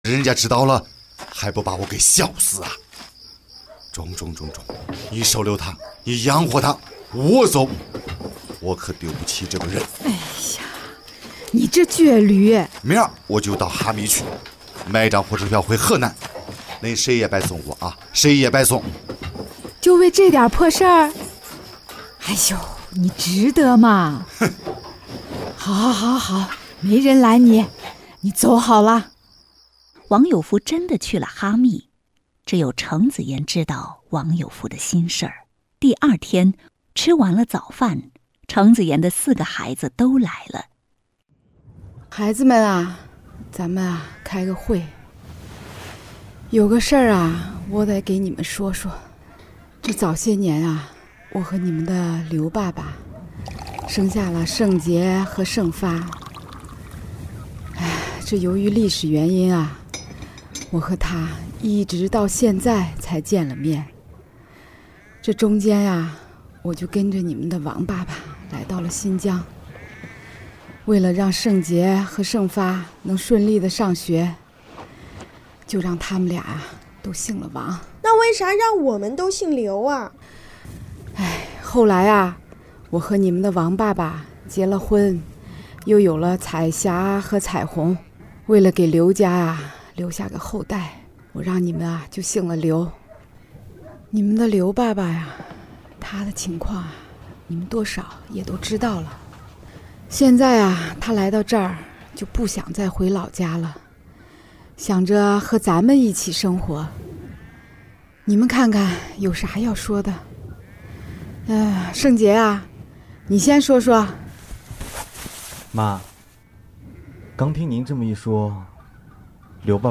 广播类型：连续剧